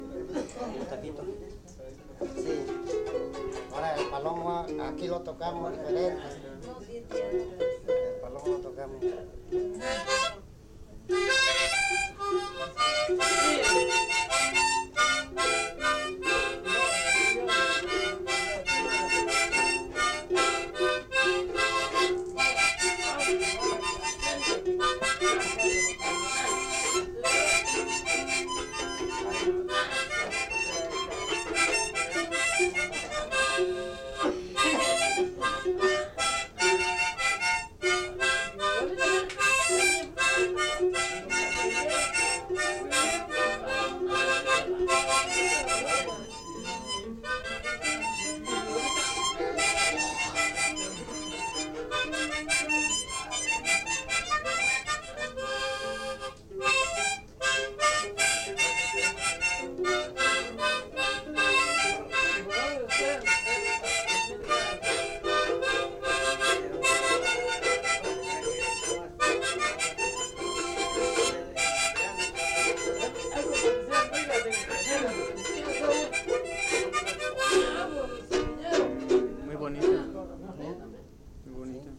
Fiesta de La Candelaria: investigación previa